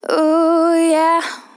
Categories: Vocals Tags: dry, english, female, fill, LOFI VIBES, OHH, sample, YEAH